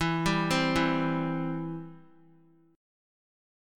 Em#5 chord